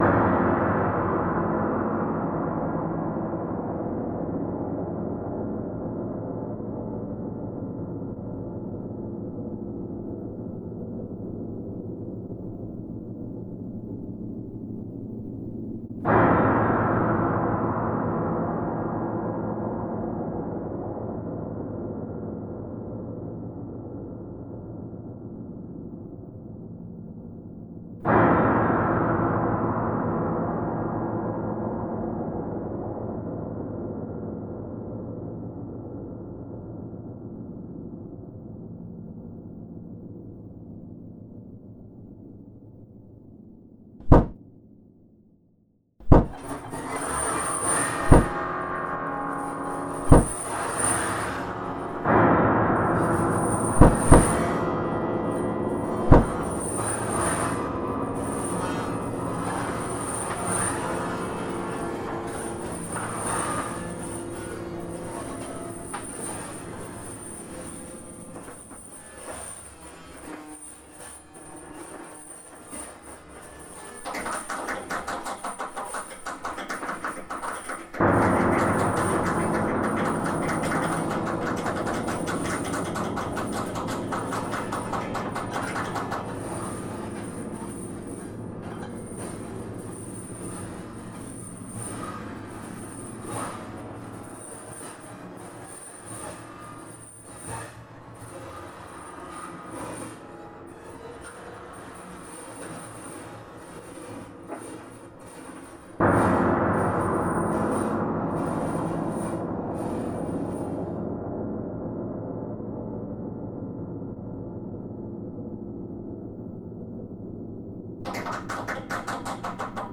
"Sinnlos verheizt" für präpariertes Klavier und Sprecherin (2023)
Das Stück „Sinnlos verheizt“ stellt ein, aus aktuellem Anlass konzipiertes Anti-Kriegs-Stück dar, in dessen Rahmen Kriegsgeräusche am Flügel erzeugt und zu einem akustischen Werk zusammengestellt werden. Neben Clustern auf den Klaviertasten werden weitere perkussive Elemente wie Metallketten verwendet. Als Kontrast gibt es eine von einer Sprecherin aufgenommene Tonspur auf Russisch, die die Verzweiflung und das innere Empfinden an Kampfhandlungen beteiligter Kombattanten beider Seiten vor dem Hintergrund der akustischen Kriegsszenerie darstellt. Alle Elemente wurden anschließend digital bearbeitet und zu einem schlüssigen Ganzen zusammengefügt.